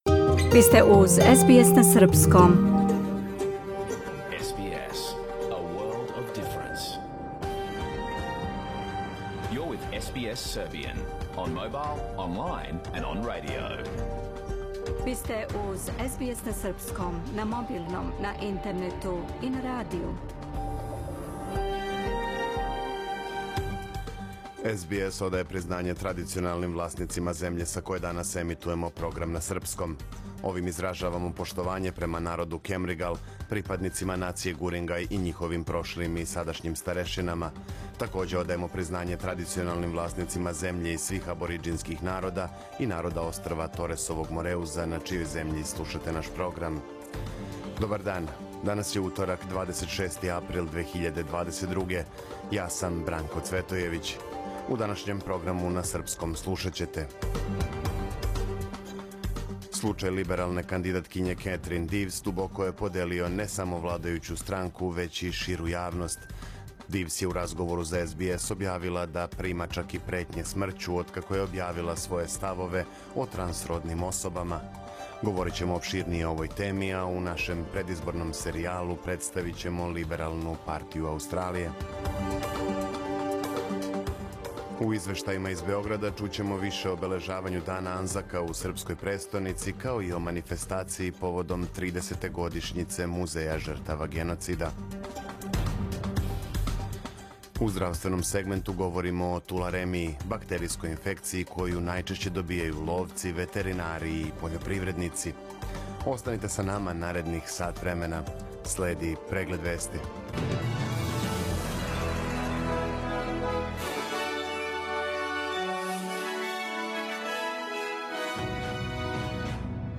Програм емитован уживо 26. априла 2022. године
Ако сте пропустили нашу емисију, сада можете да је слушате у целини као подкаст, без реклама.